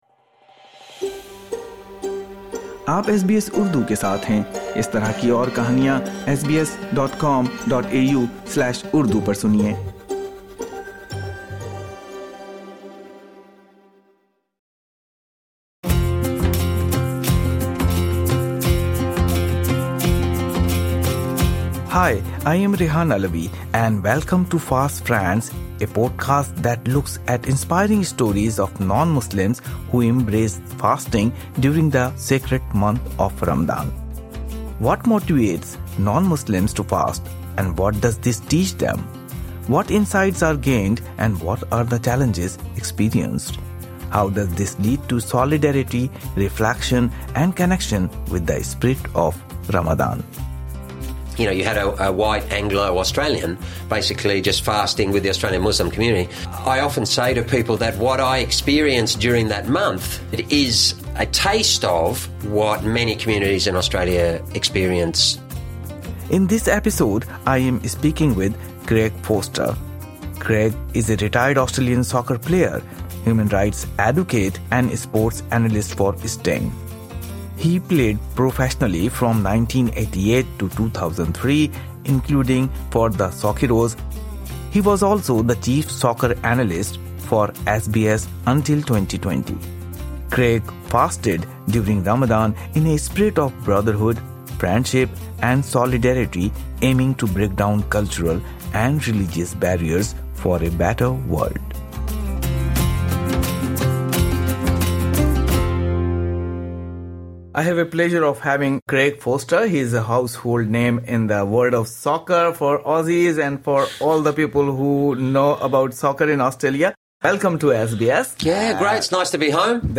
In this episode, former soccer star and human rights advocate Craig Foster AM, talks about why he chooses to fast in solidarity, and how he thinks it can help foster cultural understanding.